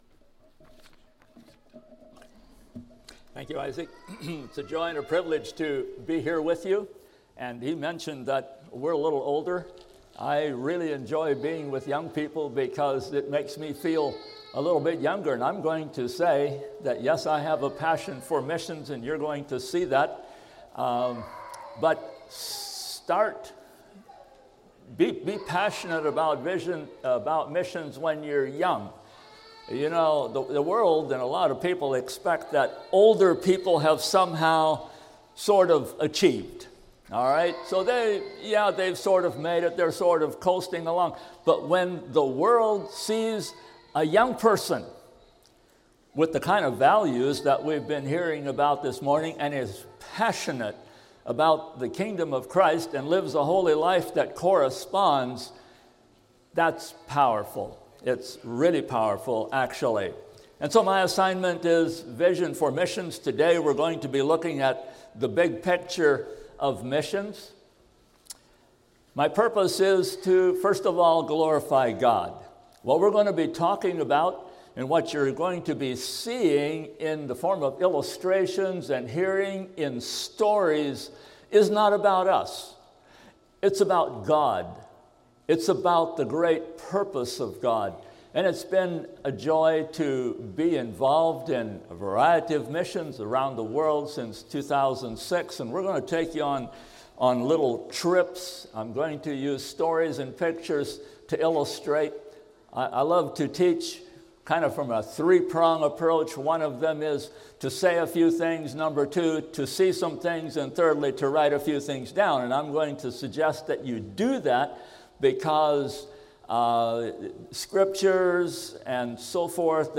A message from the series "Bible Boot Camp 2024."